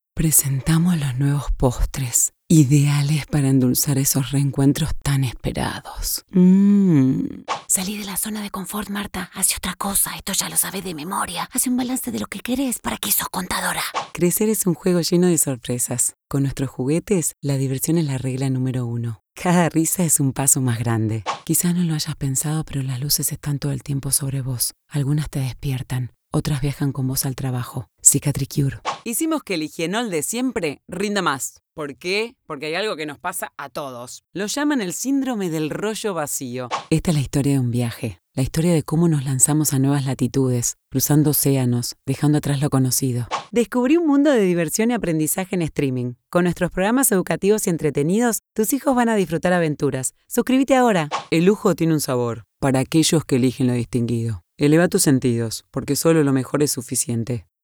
COMMERCIAL DEMO IN ARGENTINE SPANISH 2024
Middle Aged
I provide Argentine Spanish and Neutral Latin American Spanish with a natural, clear, and conversational tone, suitable for advertising, corporate content, and educational platforms.
Professional home studio, fast turnaround, and directed sessions available.